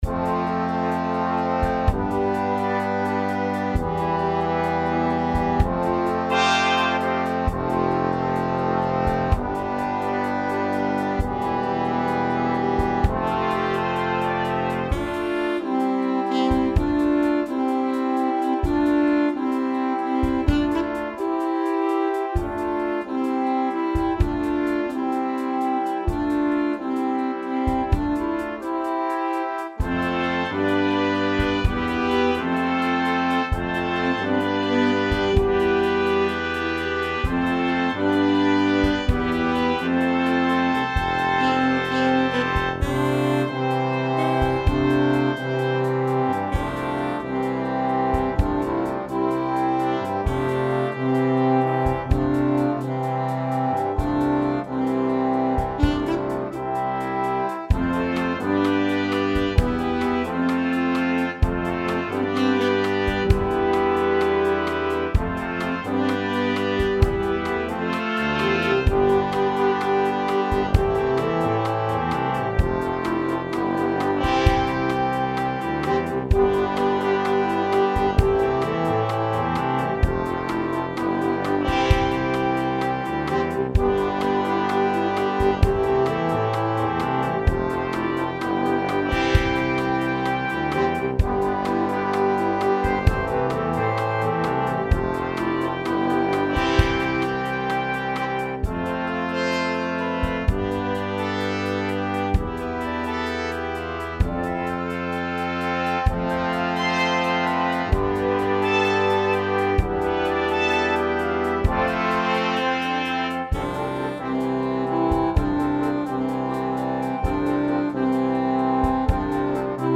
Sólová skladba pre saxofón a dychovku
Sólo pre Alt saxofón a dychovú hudbu